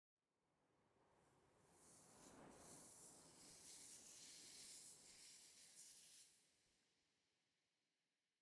movingsand2.ogg